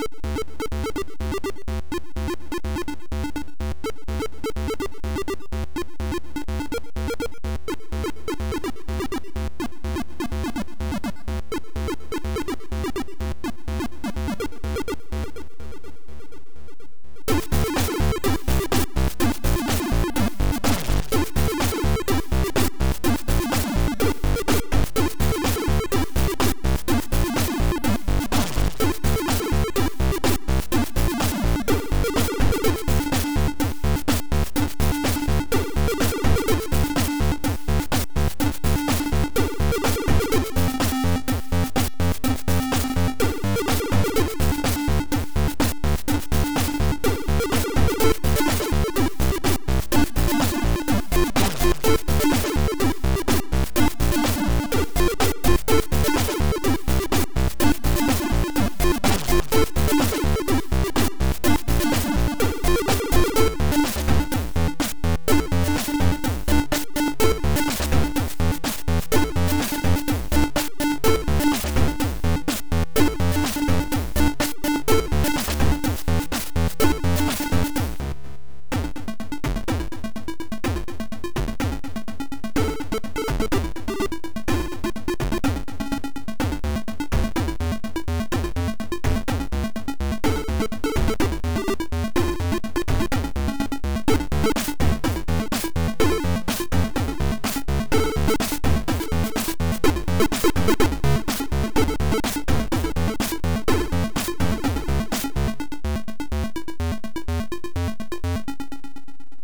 • Chip music
• Music is loop-able, but also has an ending